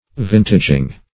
Vintaging \Vint"a*ging\, n. The act of gathering the vintage, or crop of grapes.
vintaging.mp3